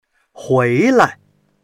hui2lai.mp3